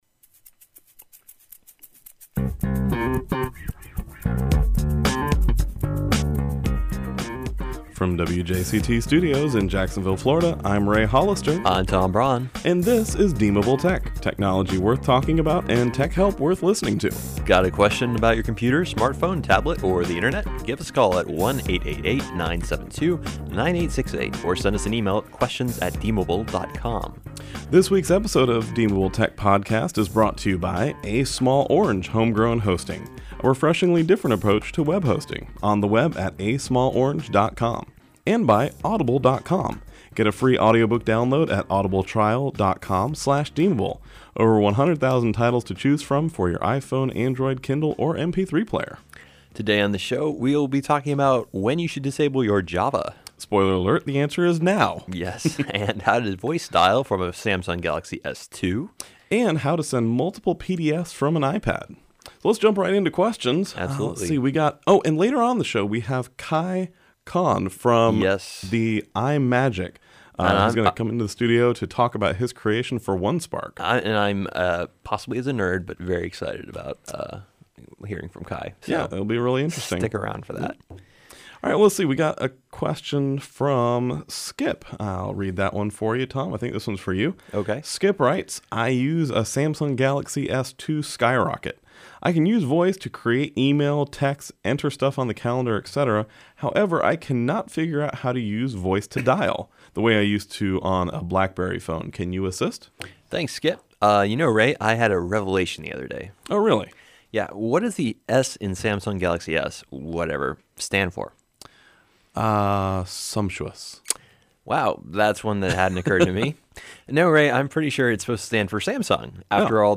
Apps, Gaming, How To, Listen, Podcast, Tech